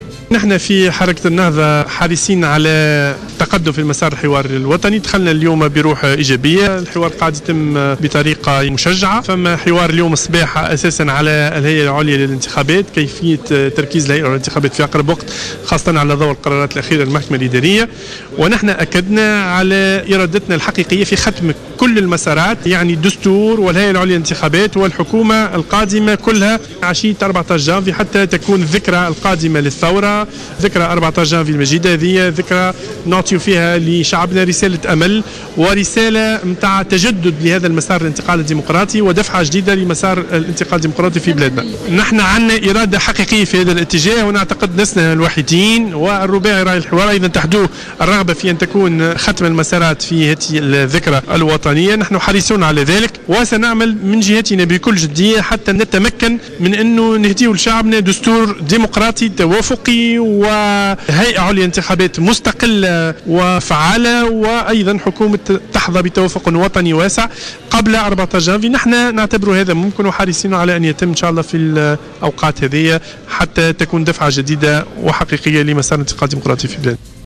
قال الناطق الرسمي لحركة النهضة في تصريح لجوهرة اف ام اليوم 23 ديسمبر 2013، أن حركة النهضة حريصة على التقدم في مسار الحوار الوطني الذي استأنف في اجواء ايجابية على حد قوله .